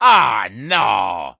Wario losing a point, prior to becoming a pile of fat, from Mario Sports Mix
Wario_(Aww,_no!)_-_Mario_Sports_Mix.oga